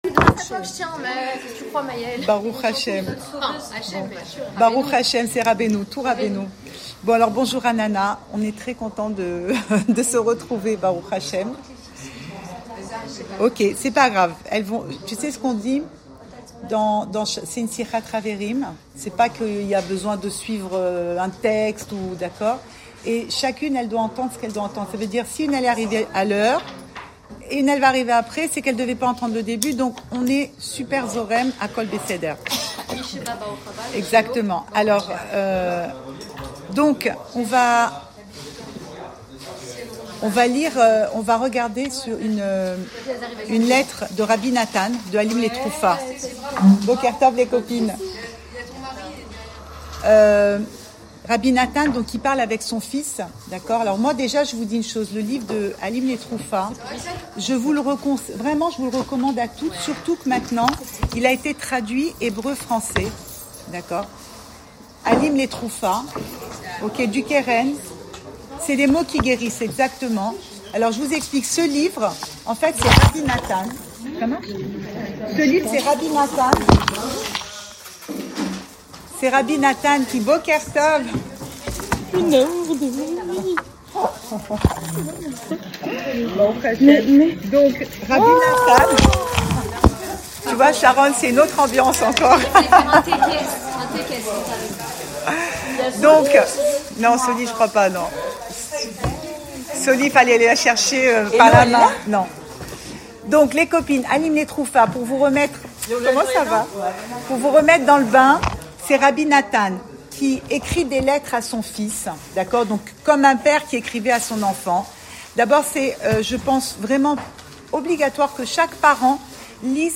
Cours audio Emouna Le coin des femmes Le fil de l'info Pensée Breslev - 14 janvier 2026 14 janvier 2026 Confiance. Enregistré à Raanana